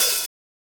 OpenHH Groovin 3.wav